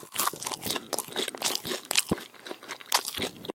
sheep-eat.ogg.mp3